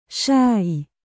音标：shāy